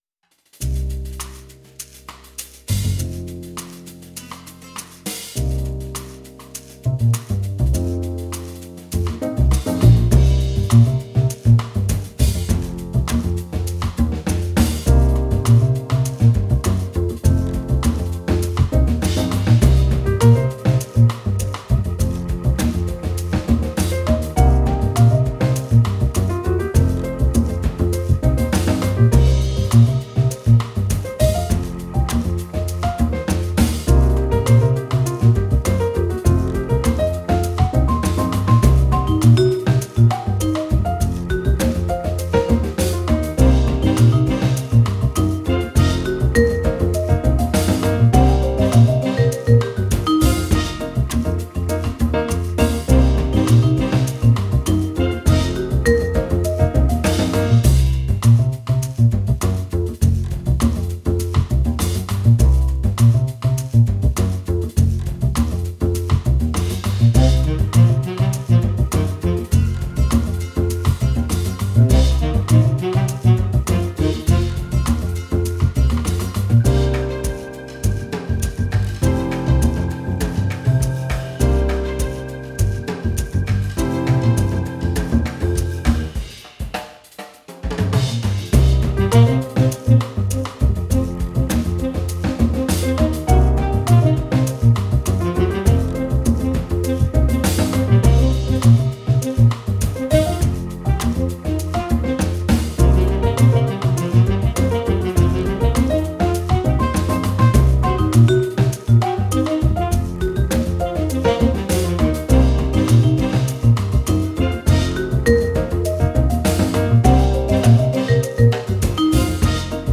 Genre: jazzfunk, jazz.